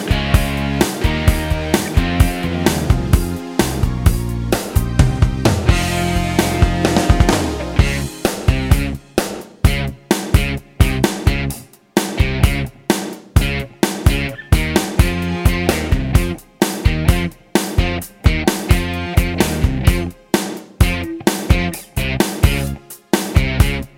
no Backing Vocals Duets 3:59 Buy £1.50